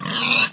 minecraft / sounds / mob / pig / death.ogg